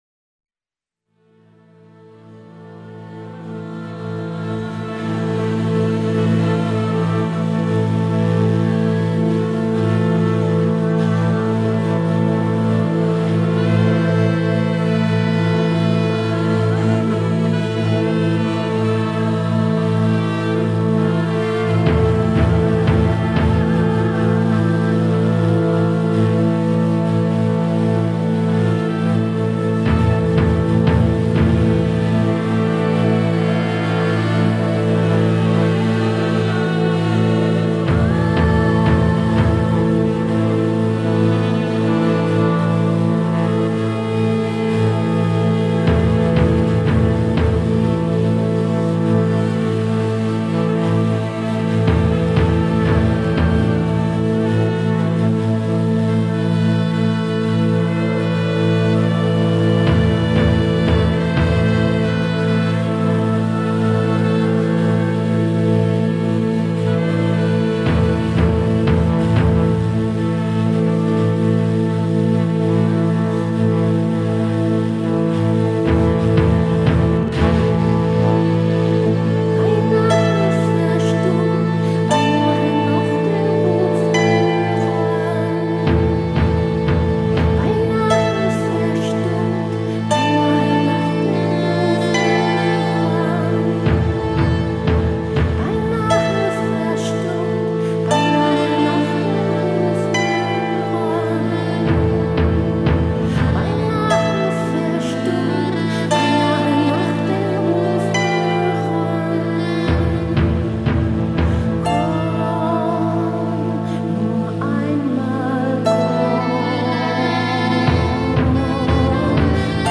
VOCALS, ZITHERS, SAXOPHONES, PERCUSSION